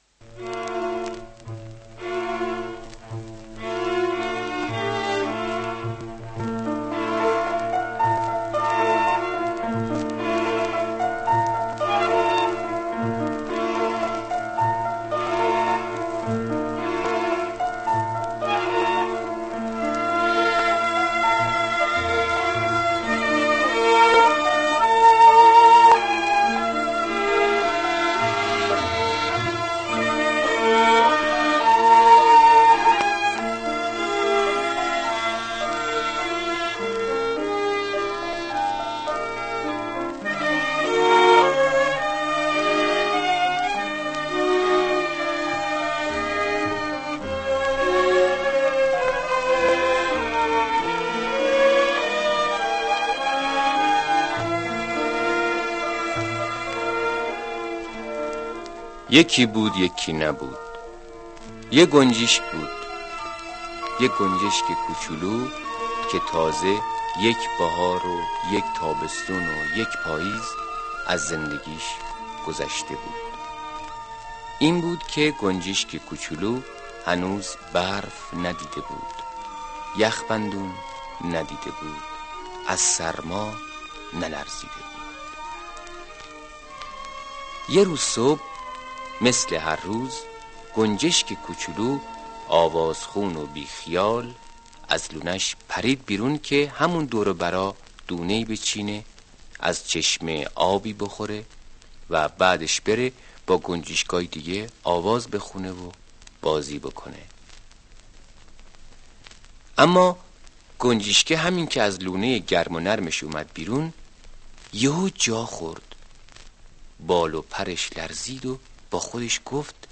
قصه کودکانه صوتی کی از همه پر زور تره؟